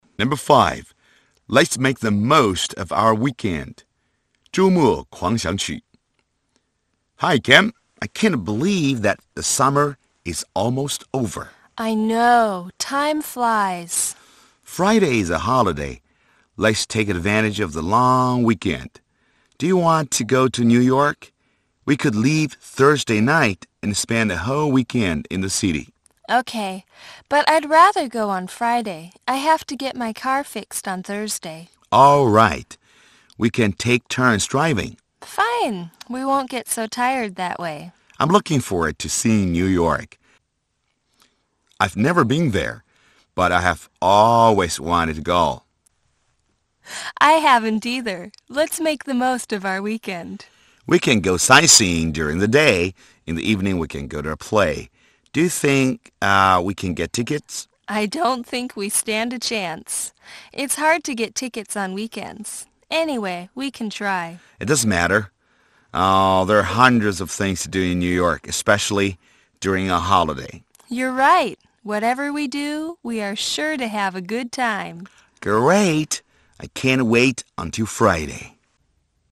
Two friends decide to go to New York for a weekend of fun.